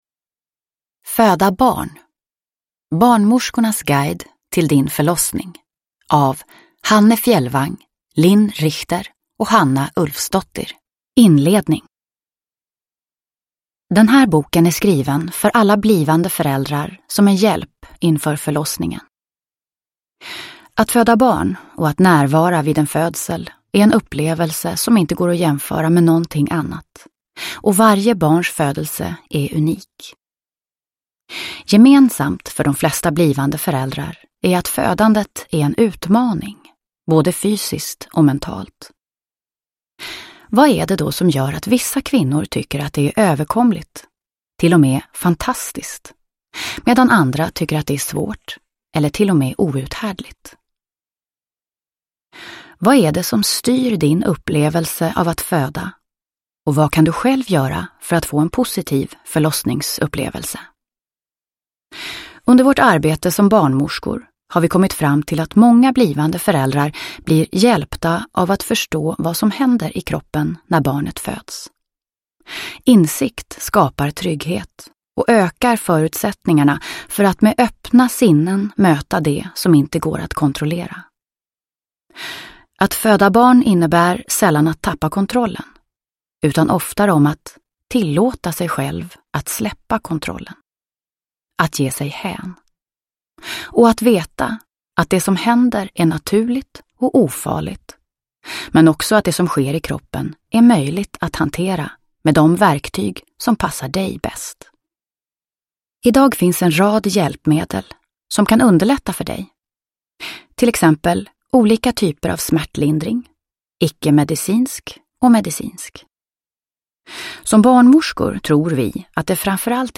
Föda barn : barnmorskornas guide till din förlossning – Ljudbok – Laddas ner